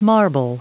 Prononciation du mot marble en anglais (fichier audio)